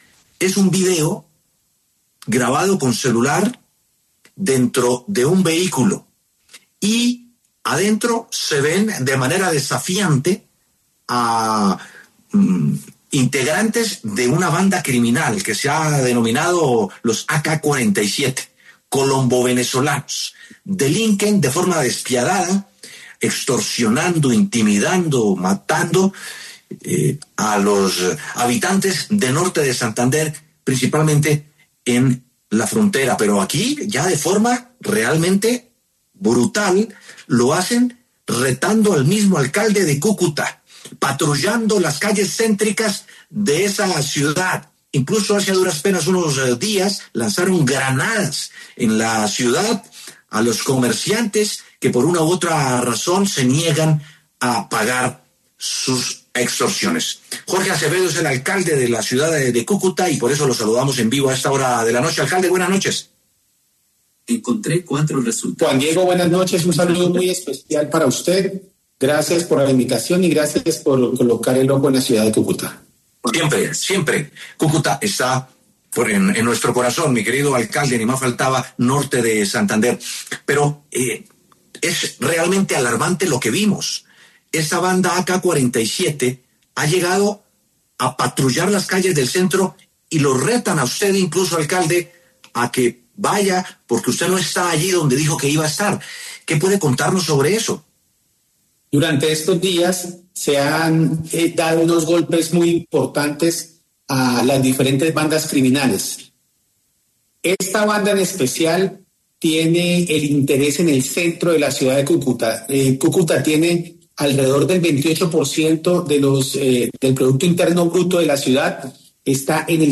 Jorge Acevedo, alcalde de Cúcuta, habló en los micrófonos de W Sin Carreta, sobre la inseguridad y los últimos hechos de violencia que se han registrado en la ciudad.